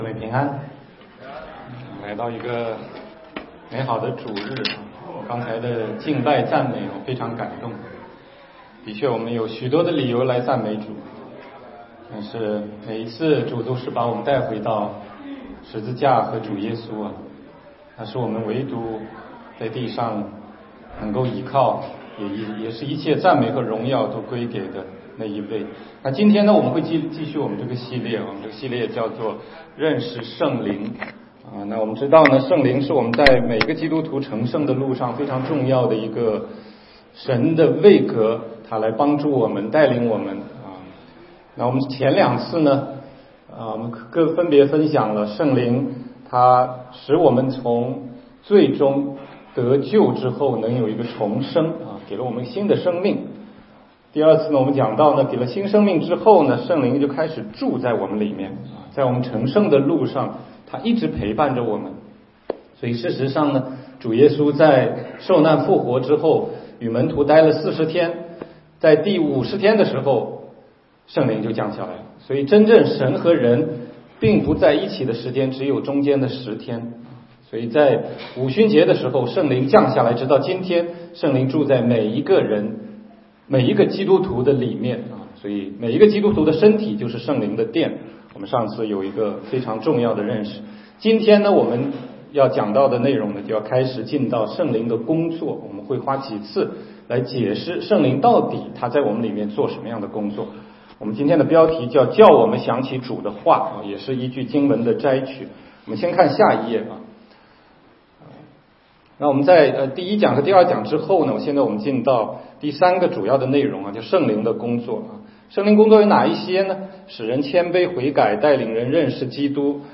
16街讲道录音 - 认识圣灵系列之三：叫我们想起主的话